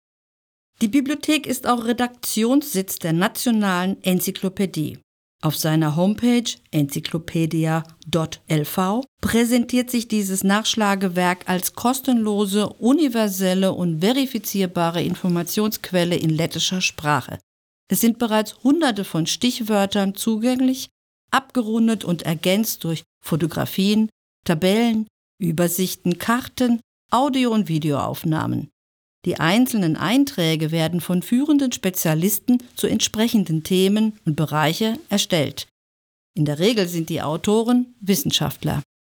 balss aktieris
Tūrisma gidi